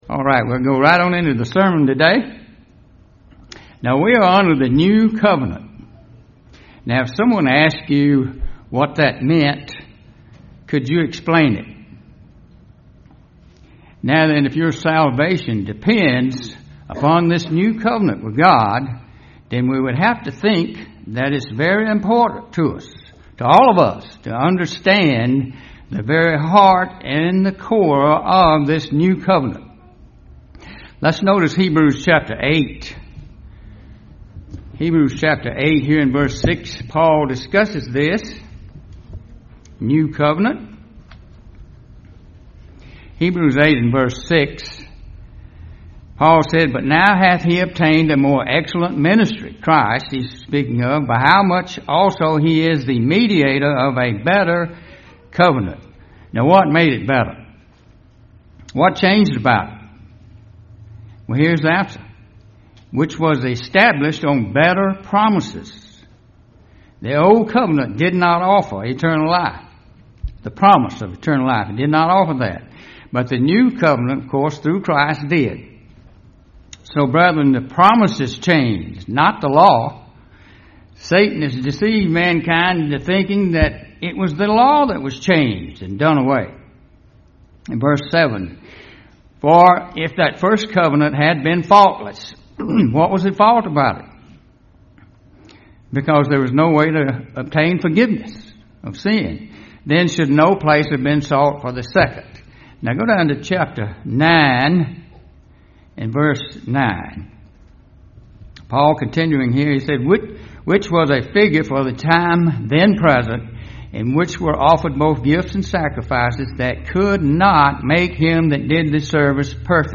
UCG Sermon Studying the bible?
Given in Columbus, GA Central Georgia